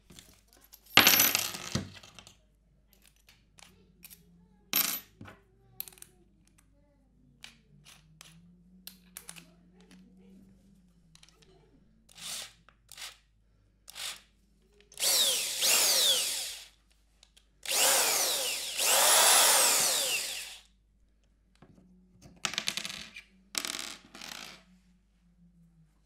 Звуки шуруповерта
Звук замены биты в шуруповерте